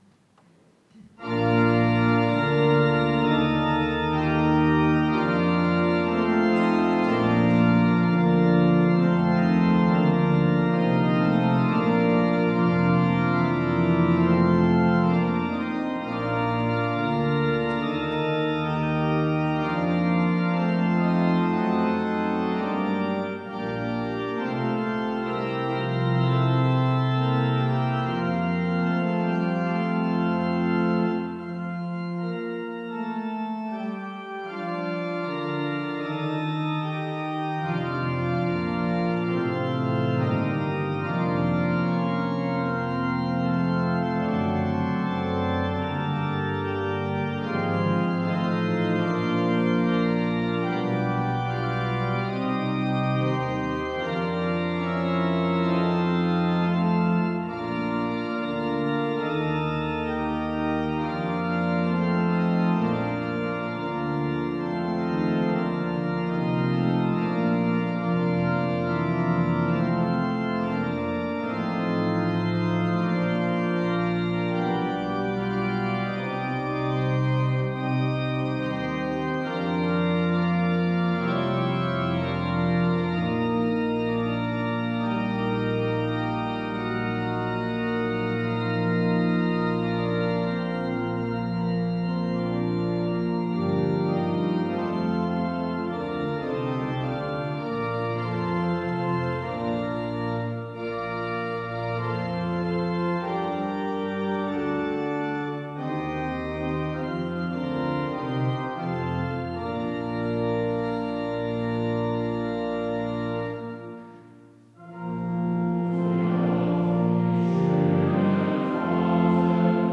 Gottesdienst am 23.02.2025
Tut mir auf die schöne Pforte... (LG 200,1-3) Ev.-Luth. St. Johannesgemeinde Zwickau-Planitz